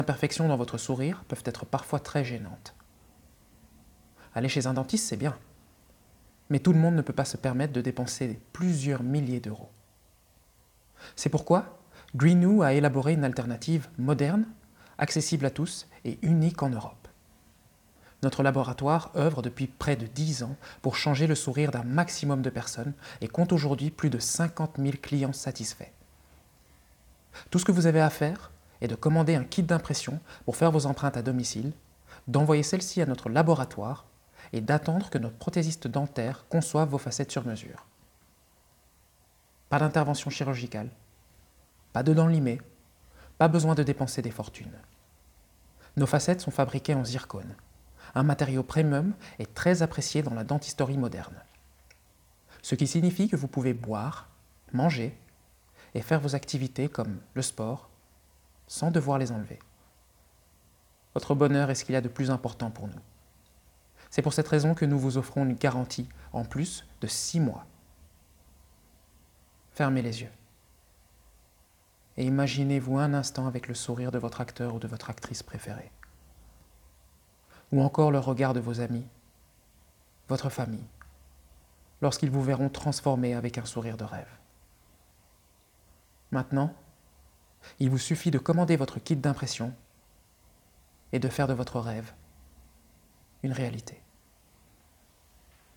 Démo audio
Voix-off-grinew-final-cut-1.mp3